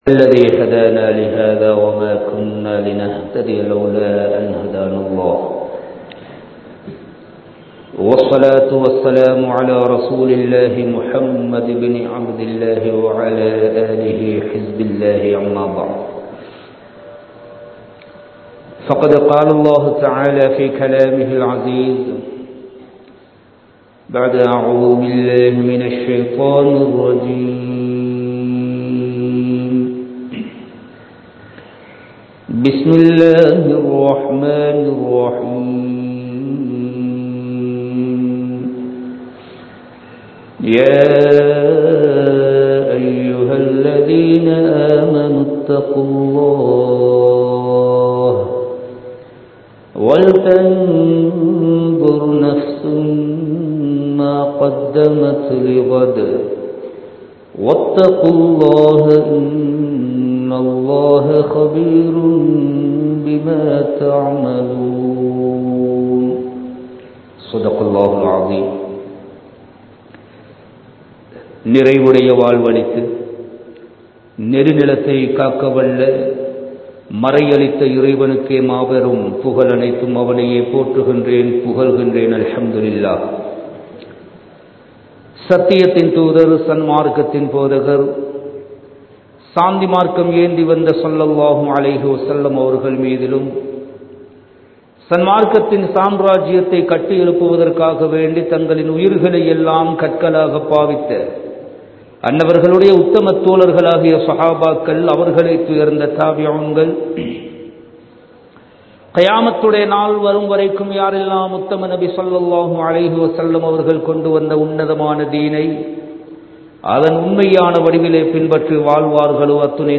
ரமலானும் ஹஜ்ஜூம் | Audio Bayans | All Ceylon Muslim Youth Community | Addalaichenai
Kandy, Kattukela Jumua Masjith